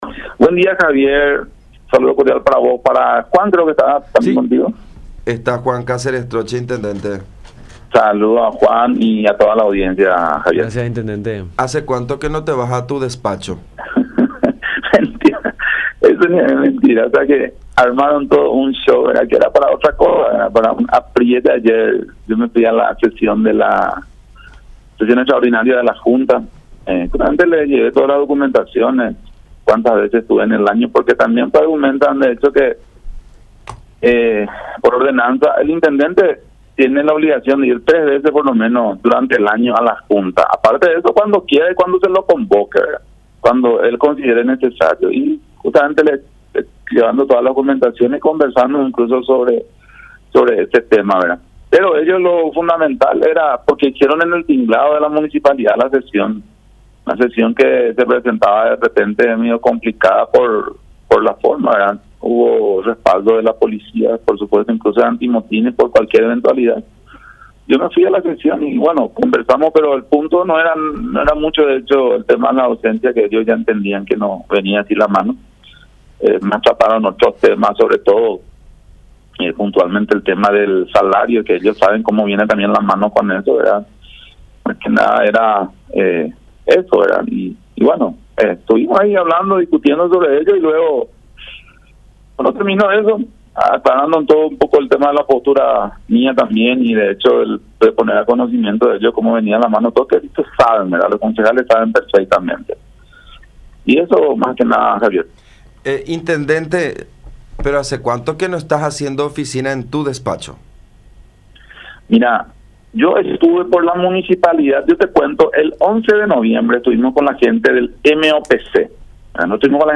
“Ayer estuve en la sesión extraordinaria de la Junta que se hizo en el tinglado municipal, llevando todas las documentaciones. Armaron todo un show con un apriete”, expresó Gómez en diálogo con La Unión, señalando que asistió a la sede municipal con resguardo policial.